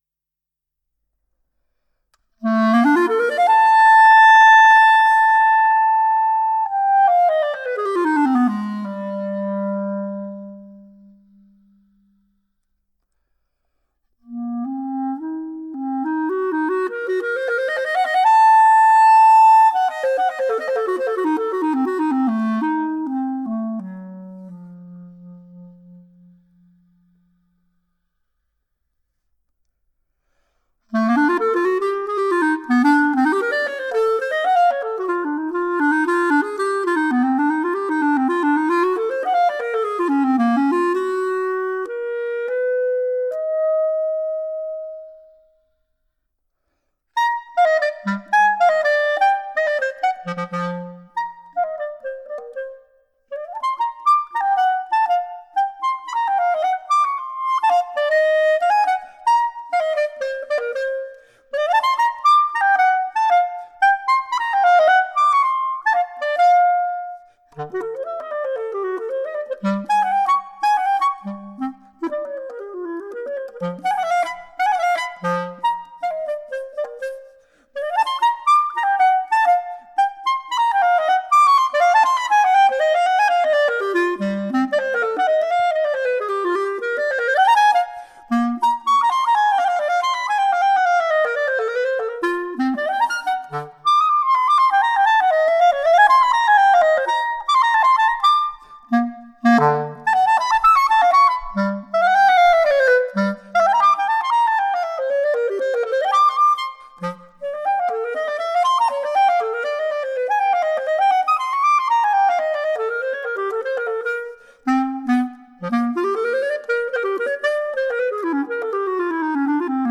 for clarinet solo